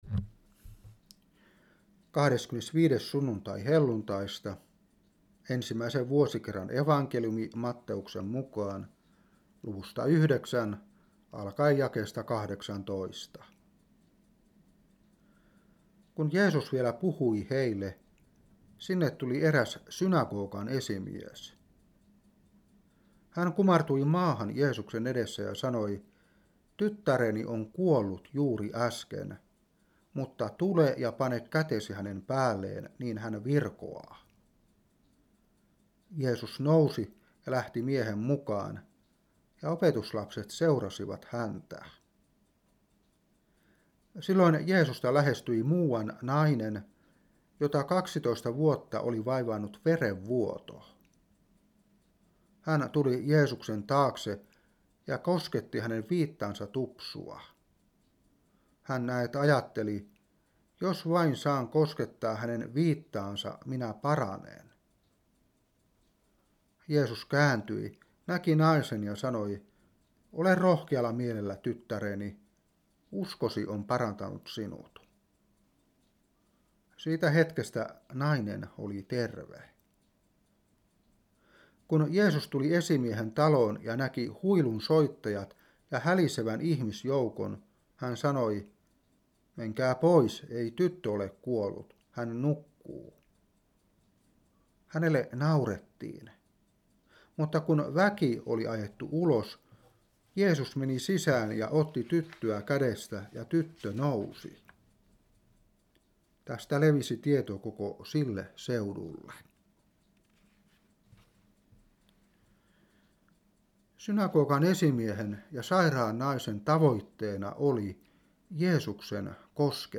Saarna 2016-11.